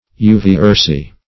Search Result for " uva-ursi" : The Collaborative International Dictionary of English v.0.48: Uva-ursi \U`va-ur"si\, n. [NL., fr. L. uva grape + ursus bear.]
uva-ursi.mp3